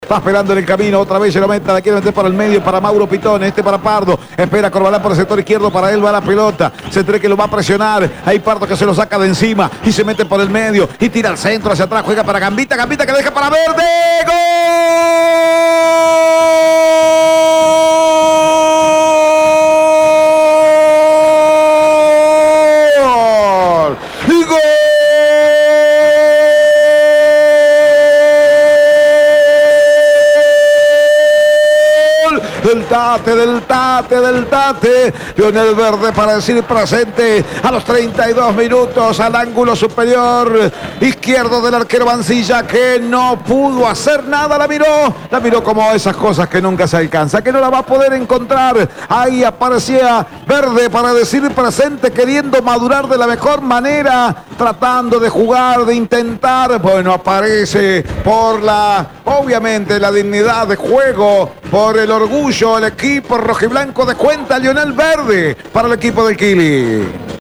Fue transmisión de Radio EME.